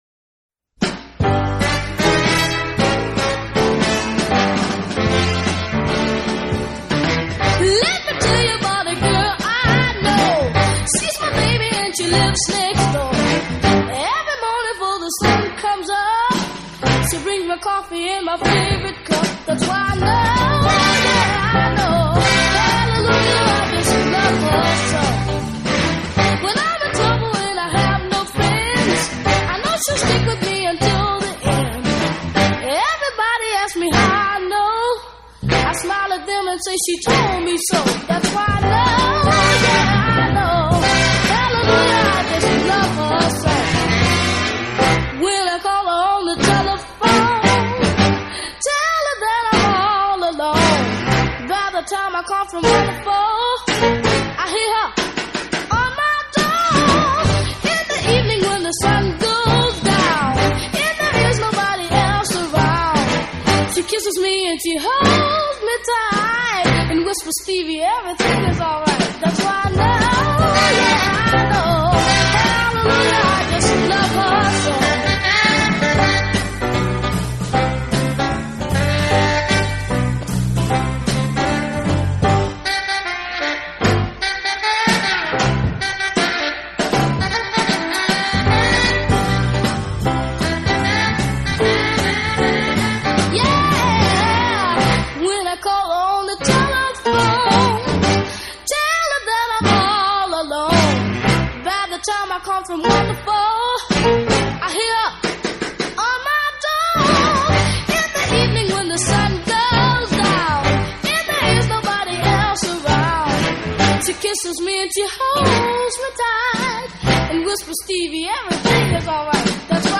Soul, R&B